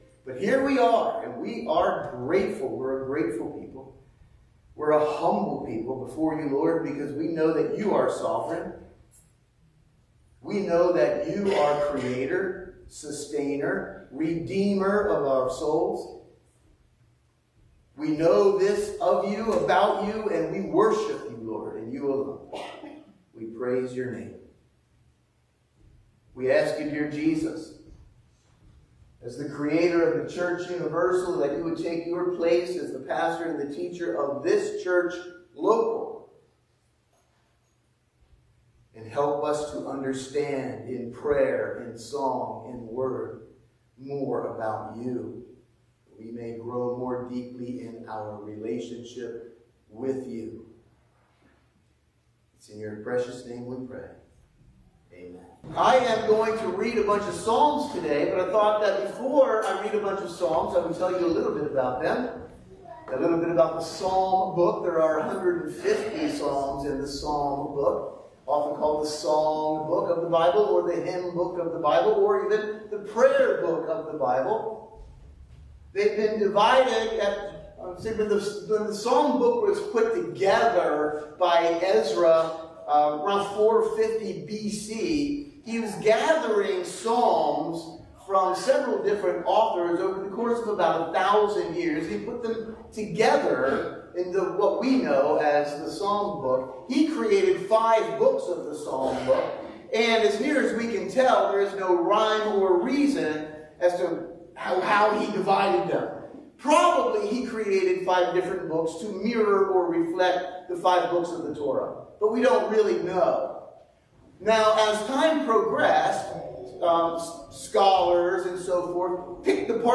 Sunday Morning Service – April 28, 2024 – Churchtown Church of God